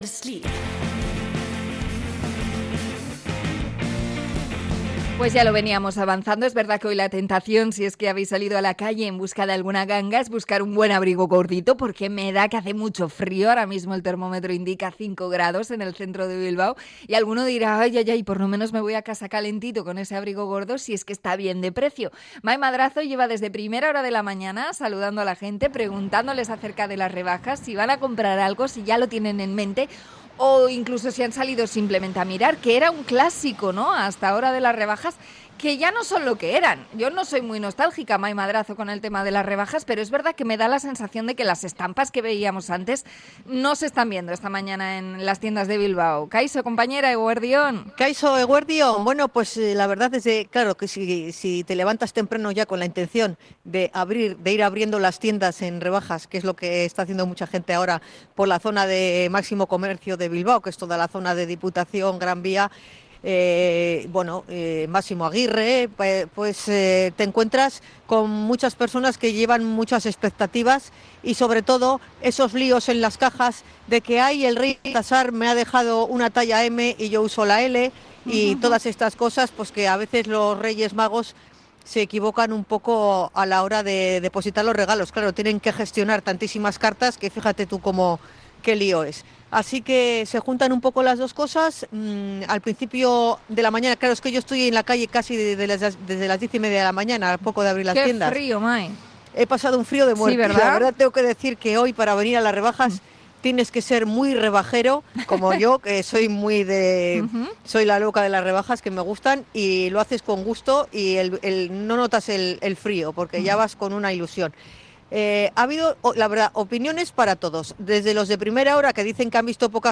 Encuesta de calle sobre el periodo de rebajas